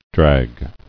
[drag]